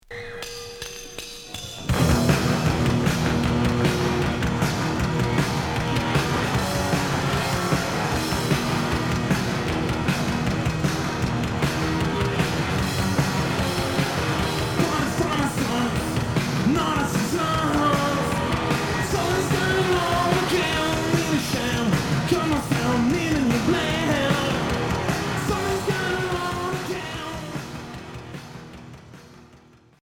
Rock garage punk